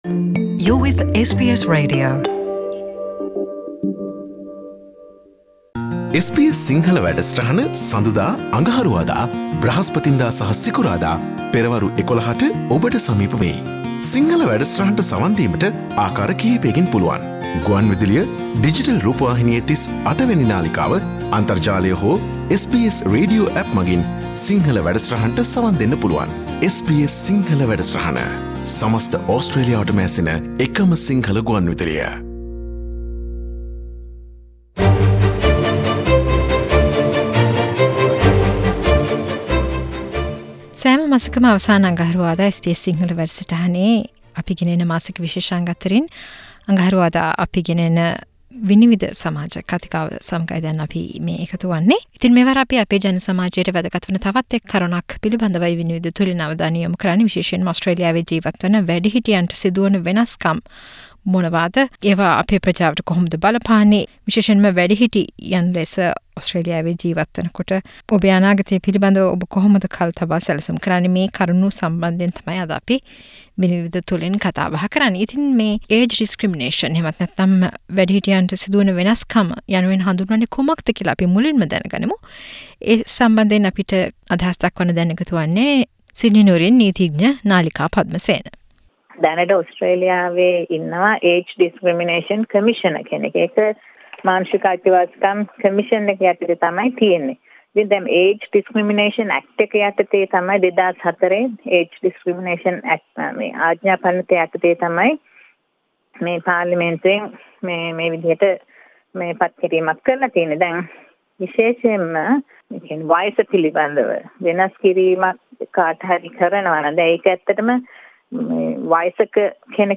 SBS සිංහල සෑම මසකම අවසන් අගහරුවාදා ගෙන එන "විනිවිද" සමාජ කතිකාව තුලින් මෙවර අපි අවධානය යොමු කරන්නේ ඕස්ට්‍රේලියාවේ වැඩිහිටියන්ට වයස මුල් කරගෙන සිදු කරන වෙනස්කම් හා ඒ සදහා ඇති නීතිමය පසුබිම අපට බලපාන ආකාරය පිලිබදවයි.